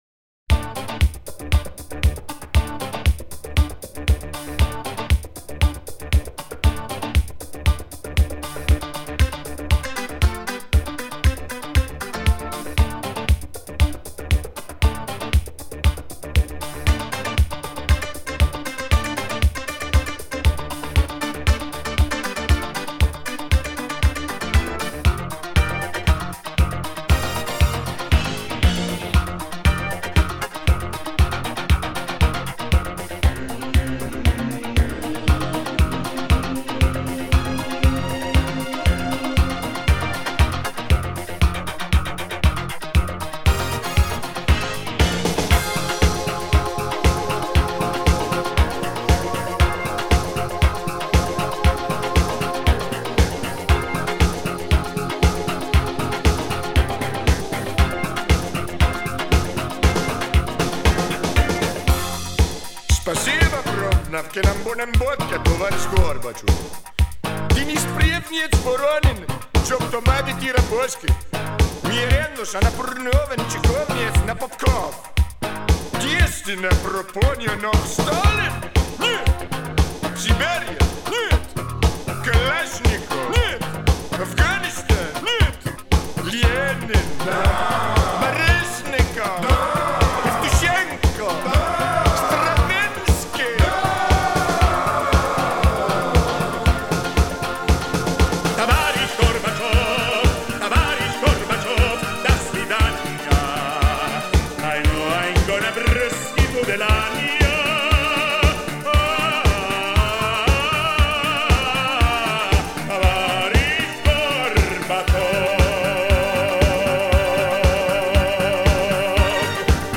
Жанр: диско